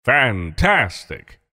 voice_tier5_fantastic.mp3